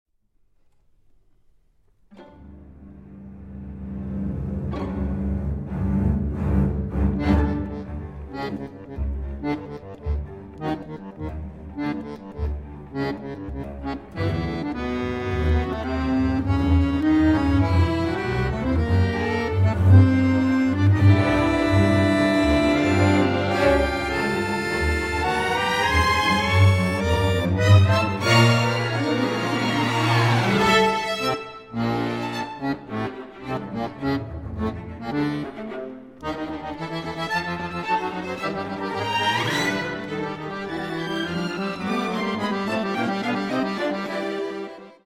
Accordion Concerto (1999) (19:18)